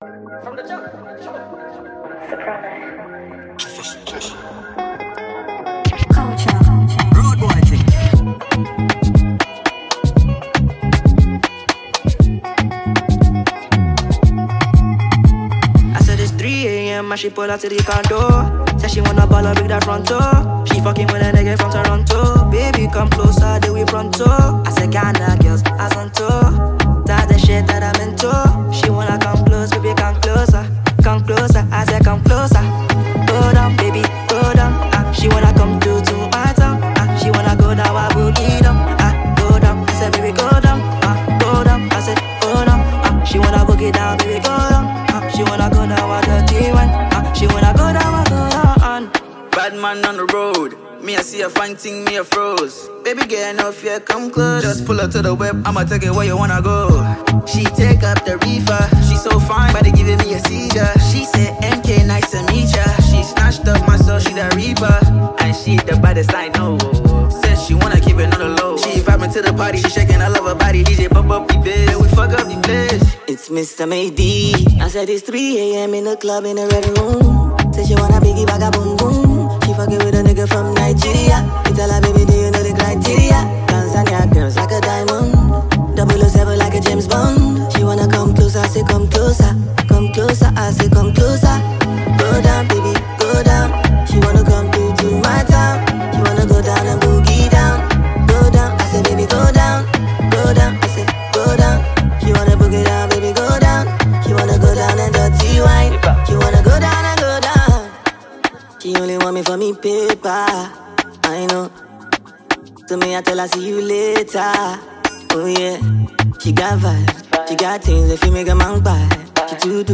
Afropop
” a soulful blend of rhythm and raw emotion.
smooth vocals over a laid-back Afrobeat production